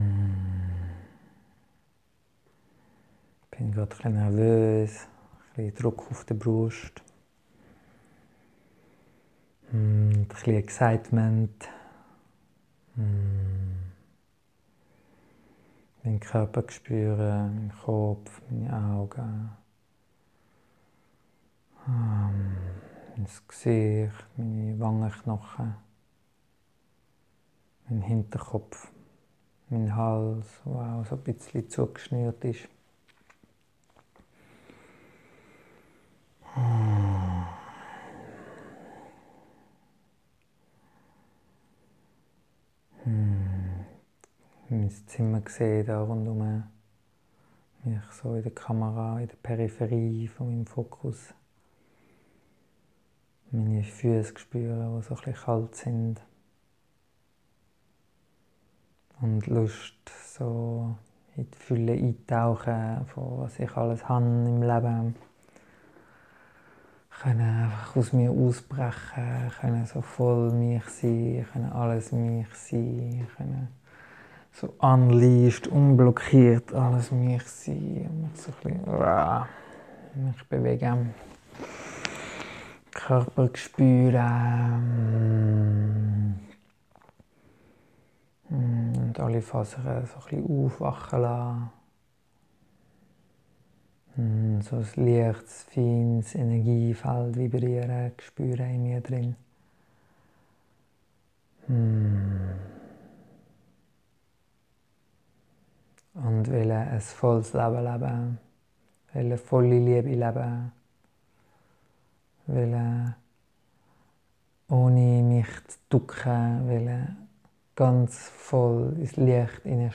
Fülle – Meditation